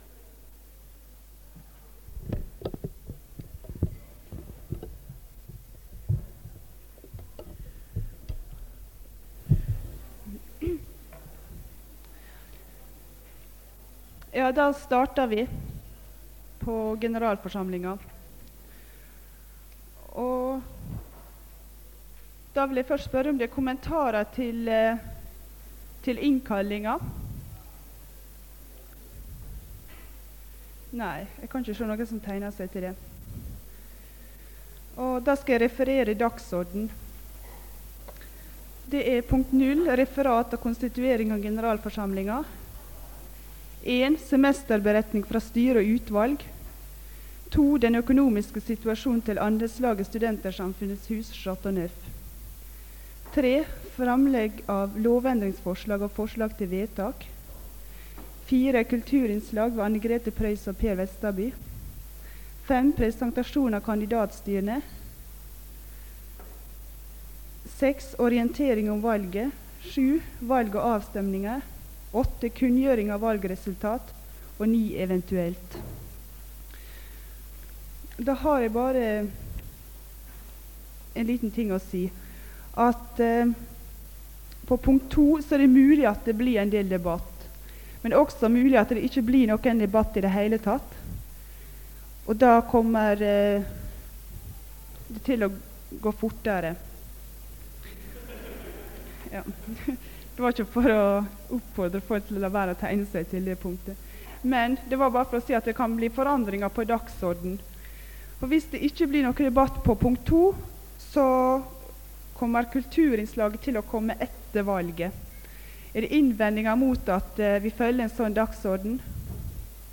Det Norske Studentersamfund, Generalforsamling, 08.05.1980 (fil 1:2)